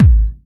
BD DM2-02.wav